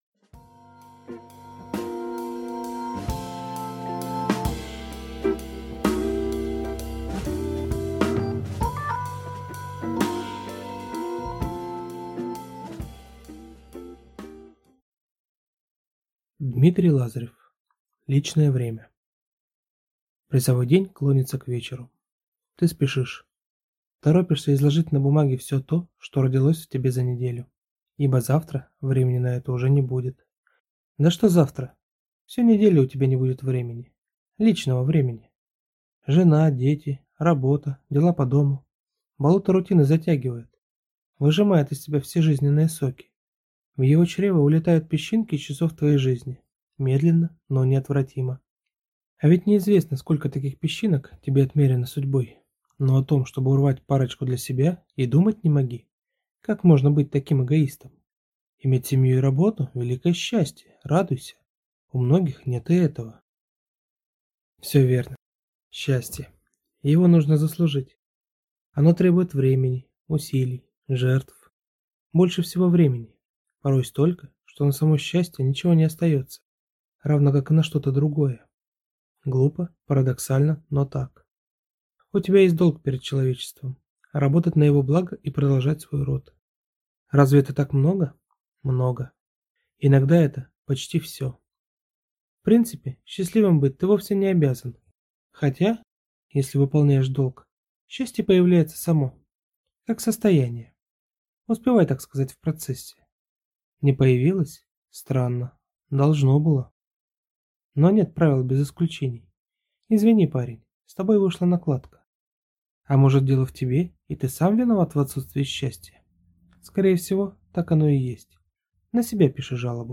Аудиокнига Личное время | Библиотека аудиокниг